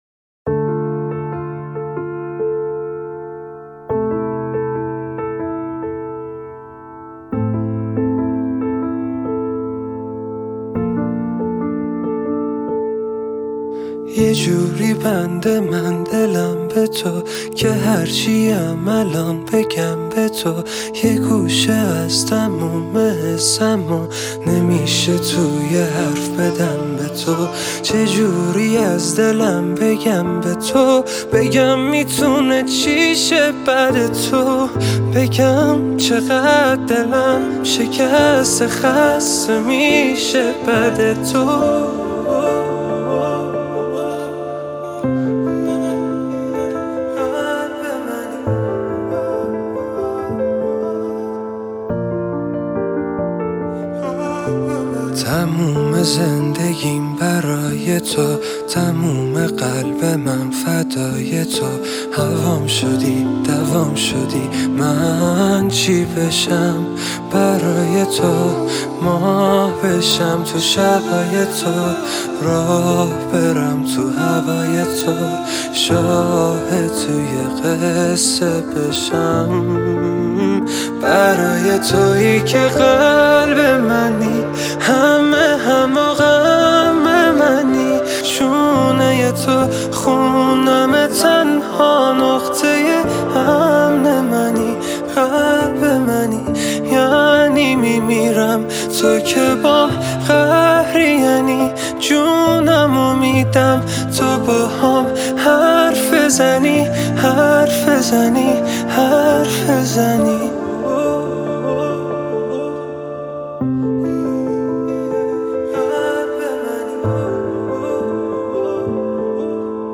دانلود ورژن پیانو